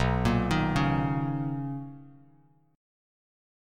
BM9 Chord
Listen to BM9 strummed